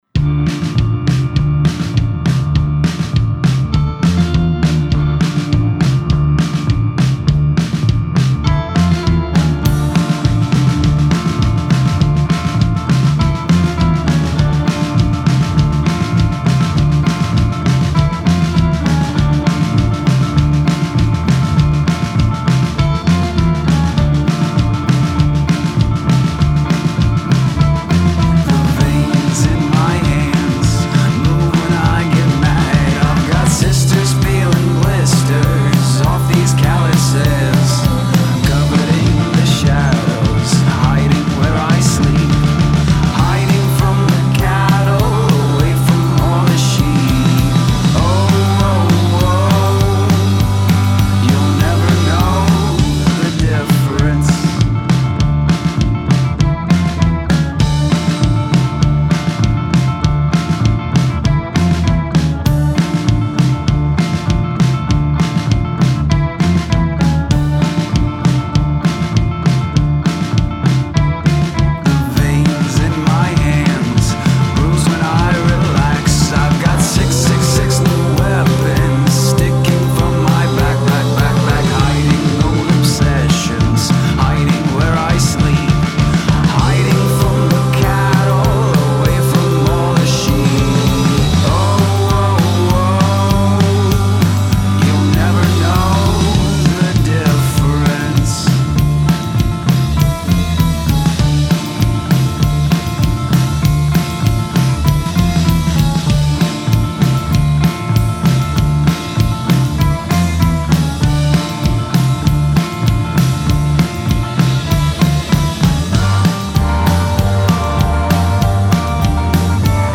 rollicking, menacing, slightly witchy track